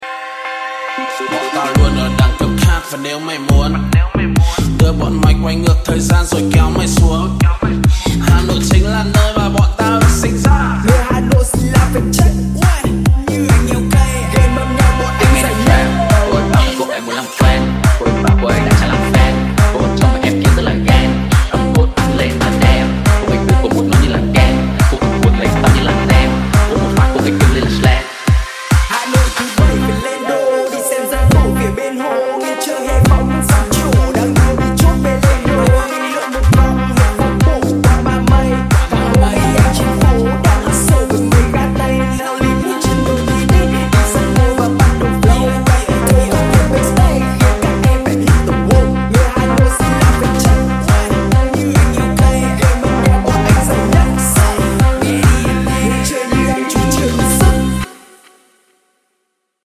Remix
#8dmusic